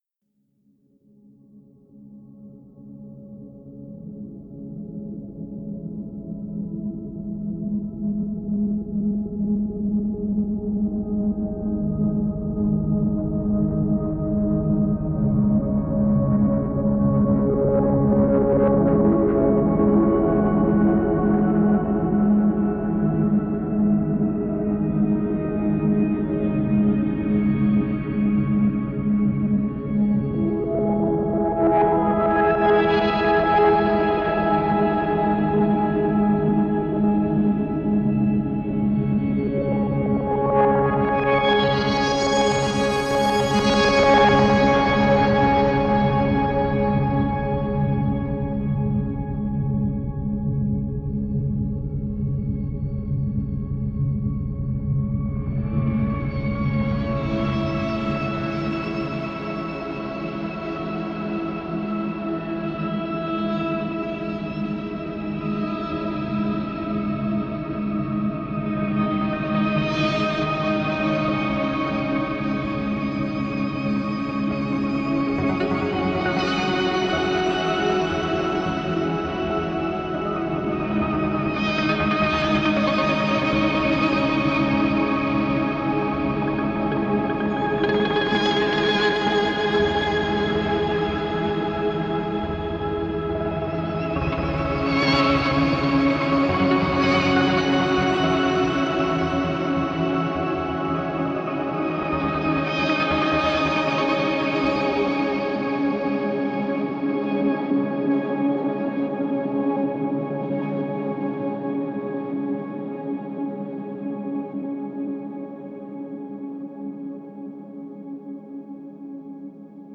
Excellent techno excursions